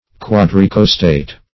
Quadricostate \Quad`ri*cos"tate\